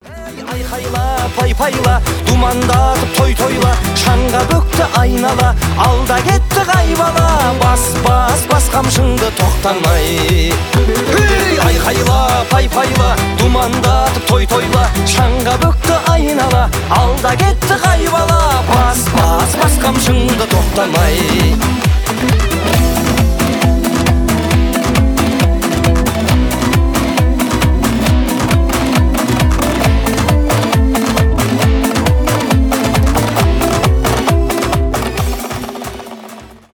2024 » Казахские » Поп Скачать припев